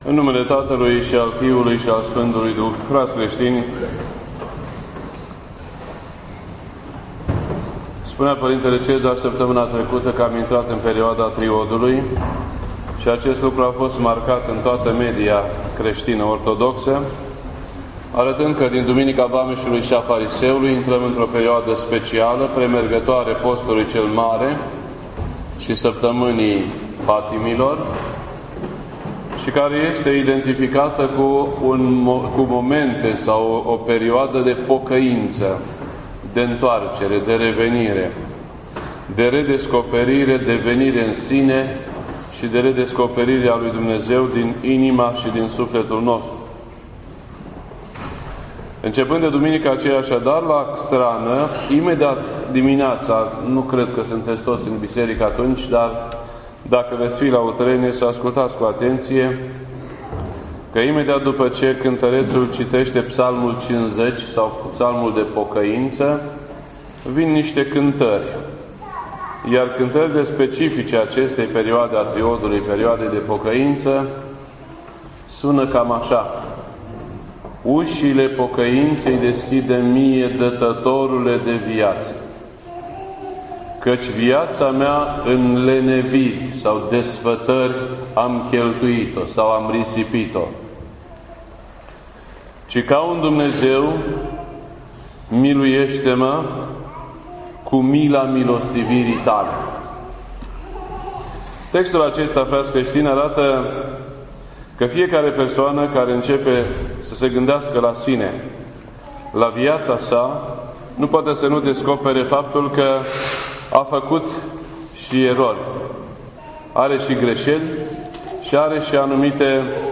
This entry was posted on Sunday, February 28th, 2016 at 6:01 PM and is filed under Predici ortodoxe in format audio.